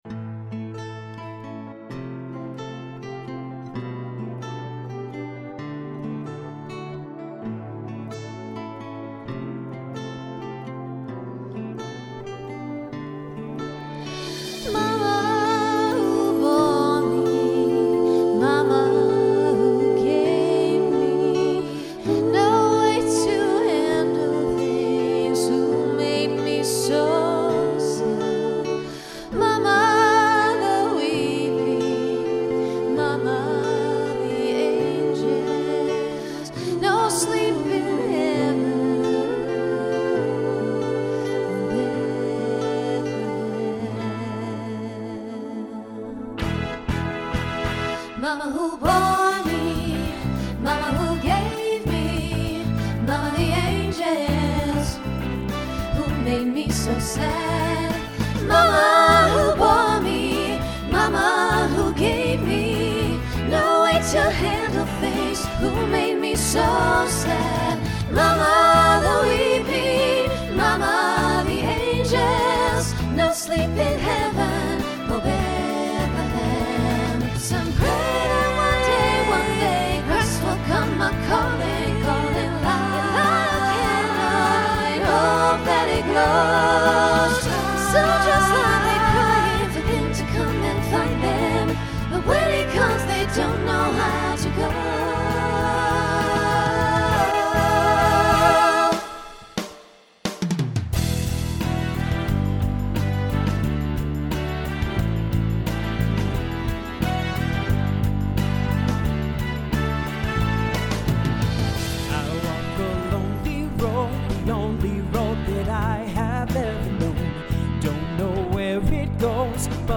Voicing SATB Instrumental combo Genre Broadway/Film , Rock